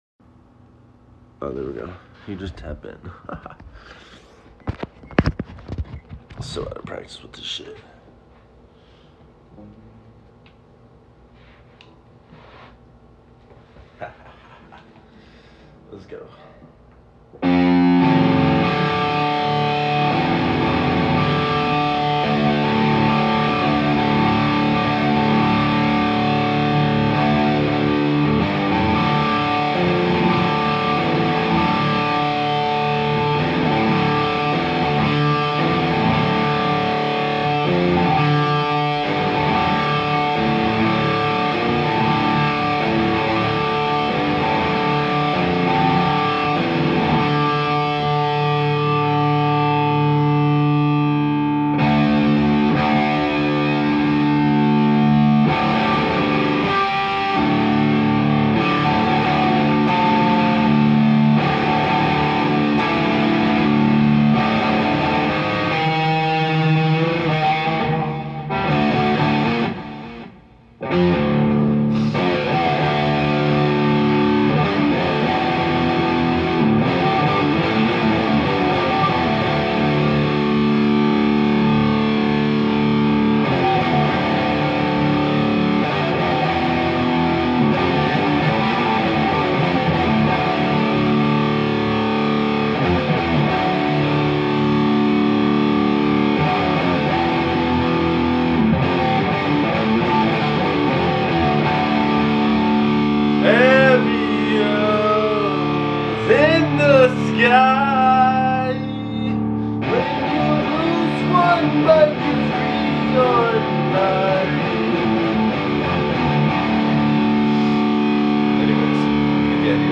Having fun with guitar be sound effects free download